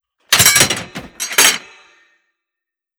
Ammo Crate Epic 003.wav